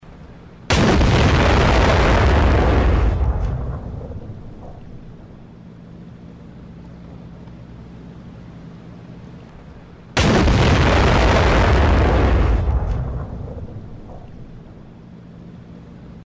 Вы можете слушать онлайн или скачать эффекты в высоком качестве: от глухих залпов тяжелых пушек до резких выстрелов легкой артиллерии.
Звук 155 мм пушки